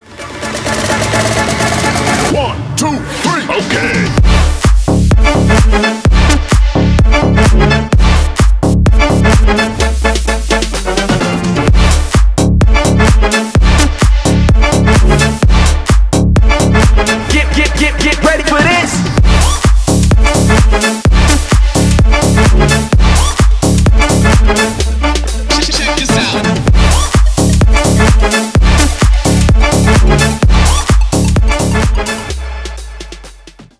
Танцевальные
ритмичные
без слов